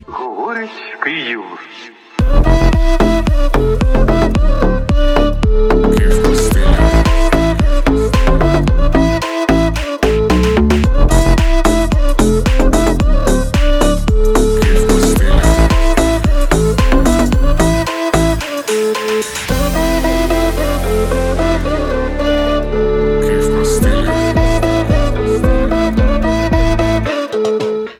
• Качество: 128, Stereo
deep house